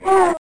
TrompetaToke.mp3